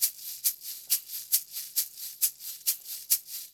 LQB SHAKER.wav